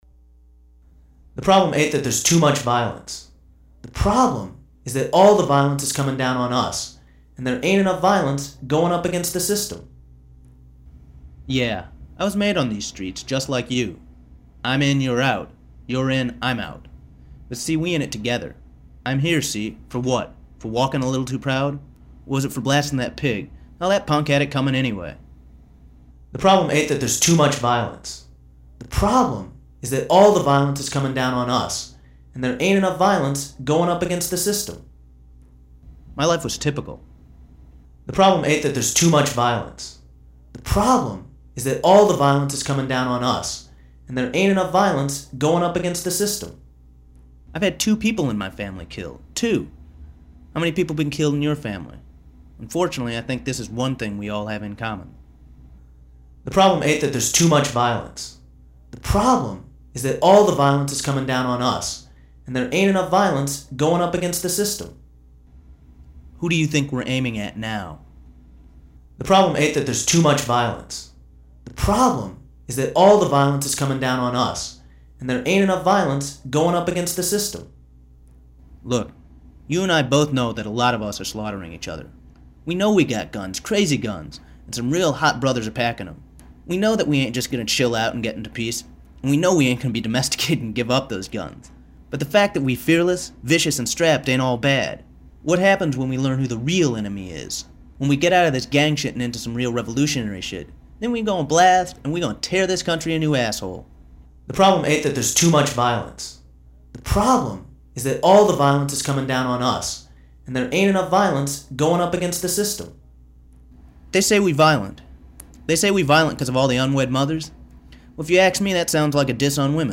When you pick up either phone in Harmed and Dangerous, you will here someone speaking about violence. The message plays in a continuous loop, thus the voices that the viewer hears depends upon the position of the tape at the time they pick up the receiver.